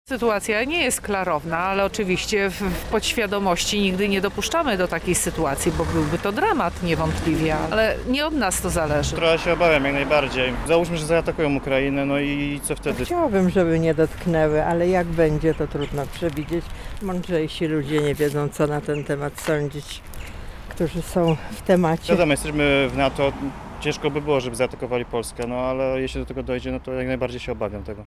Zapytaliśmy mieszkańców Lublina – Czy obawiają się, że wybuchnie wojna?
SONDA